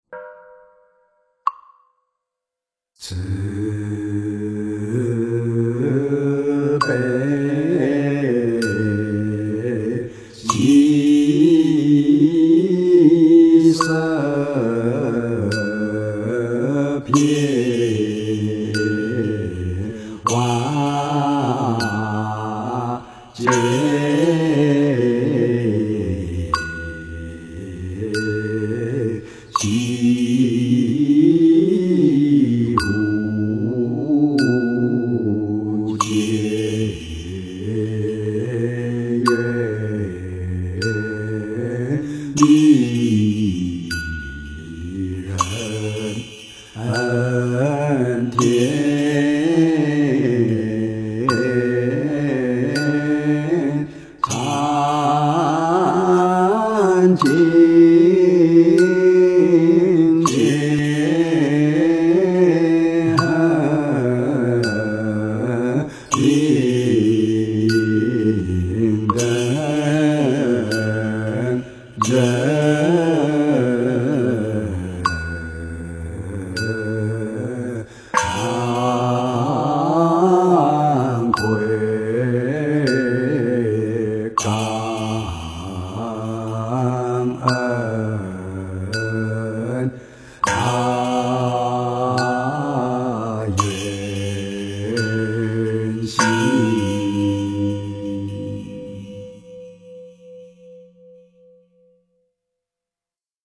回向 - 诵经 - 云佛论坛
佛音 诵经 佛教音乐 返回列表 上一篇： 观音圣号(梵文修持版